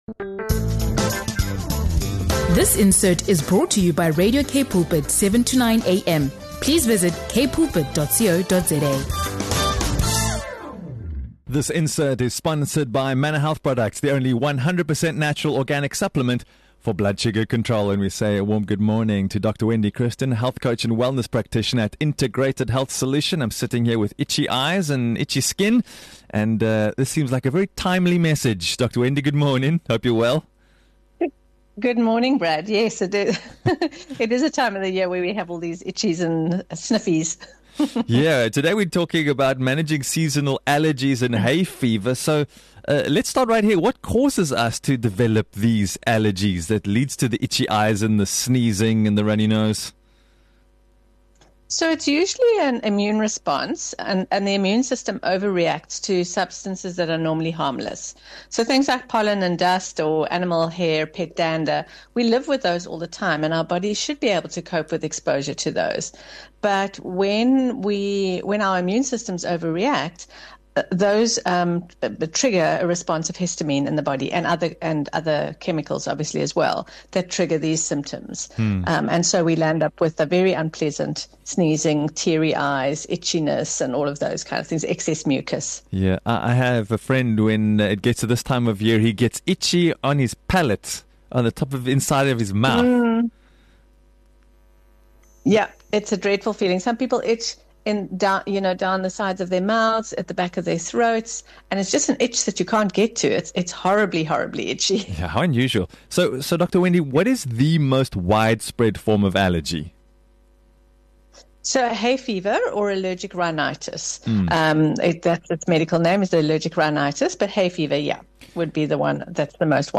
The conversation also covers the difference between common seasonal allergies and more severe reactions, setting the stage for a follow-up discussion on effective natural remedies to combat allergy flare-ups without relying solely on medication.